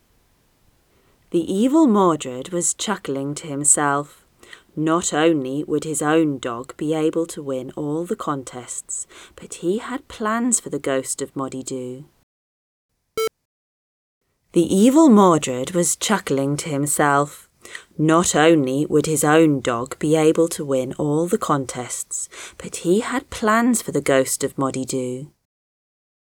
It is completely without adjustments as requested.
Those loud esses are one of the reasons it fails the ACX test.